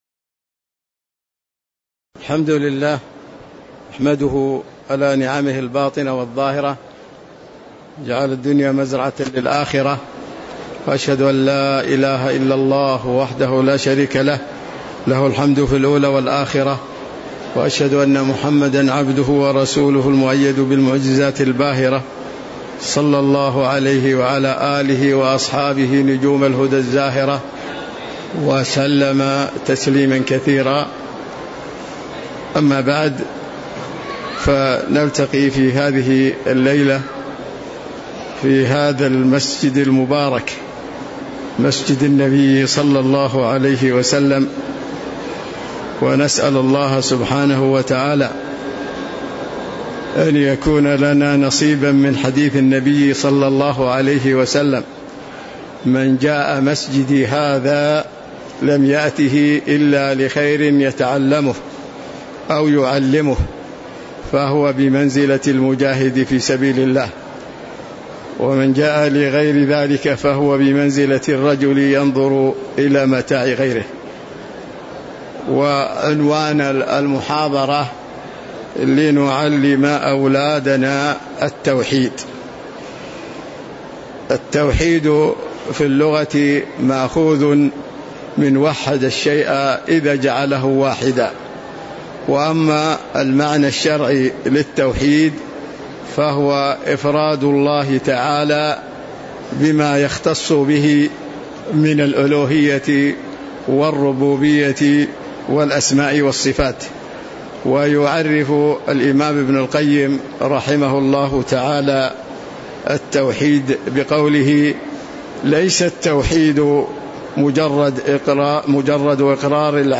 تاريخ النشر ١ جمادى الأولى ١٤٤٥ هـ المكان: المسجد النبوي الشيخ